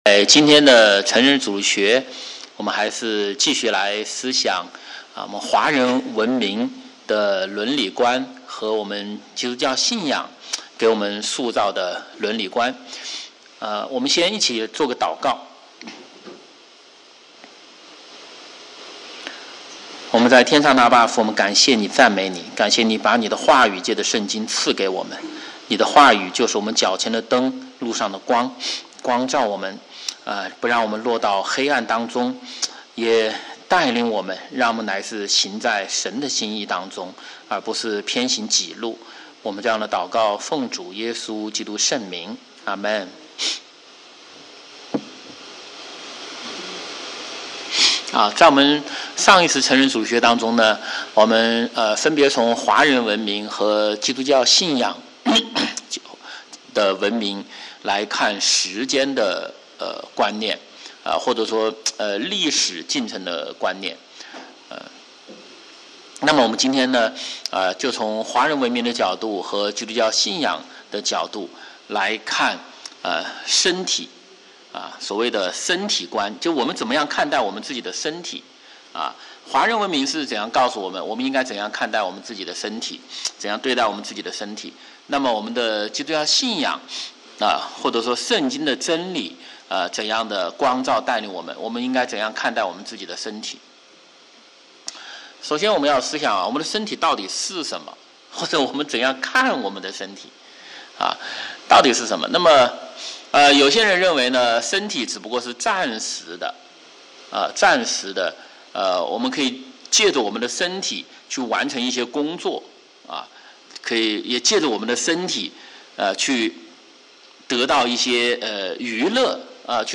主日學